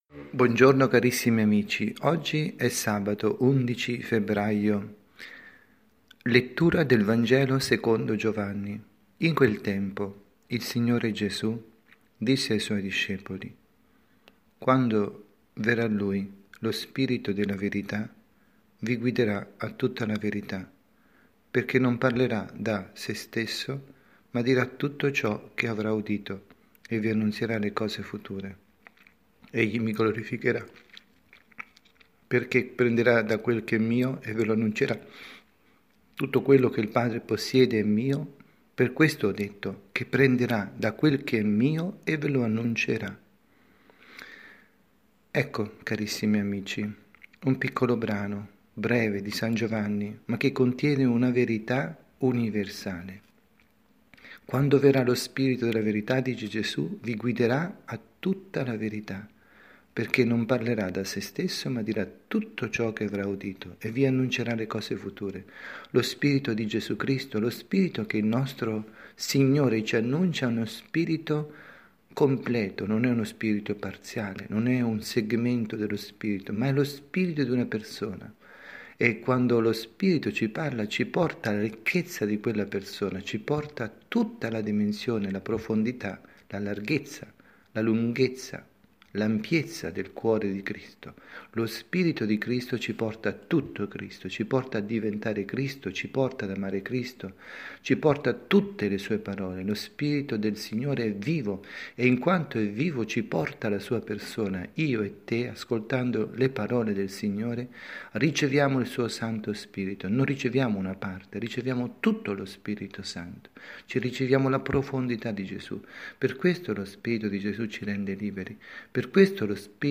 Omelia
dalla Parrocchia S. Rita, Milano